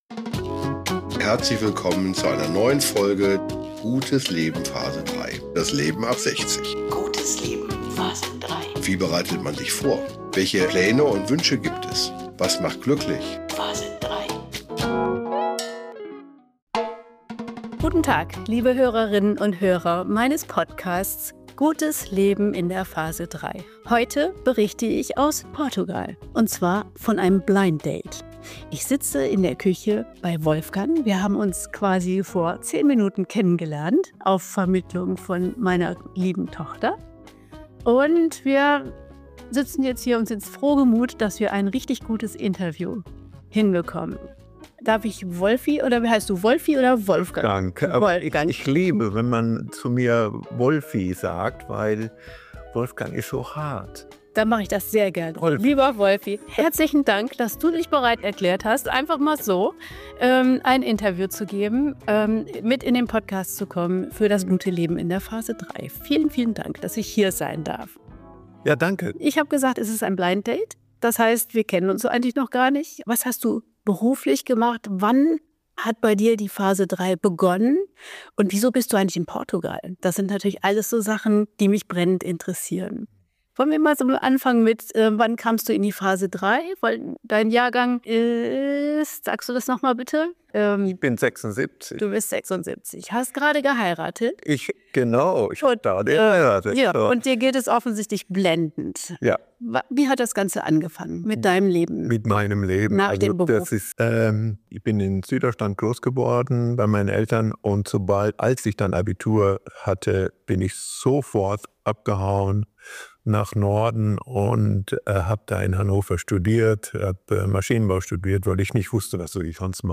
Dieses Podcast Gespräch ist ein echtes Blinddate.